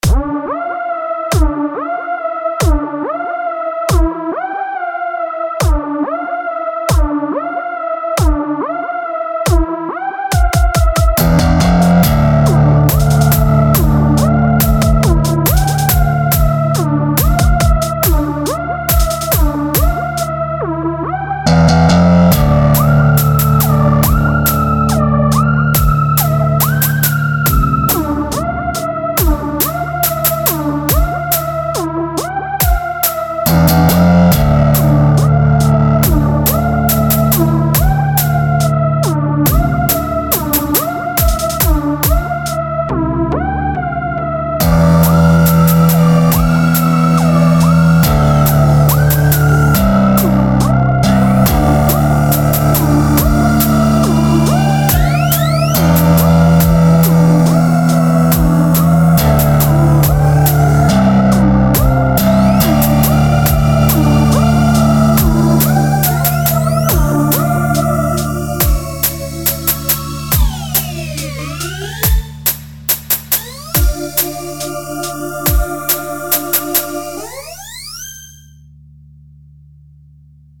I made up a couple vaguely video game-sounding songs in an attempt to get in the mood for writing it.
digital_music video_game synthesizer synth
bass_guitar bass electric_guitar guitar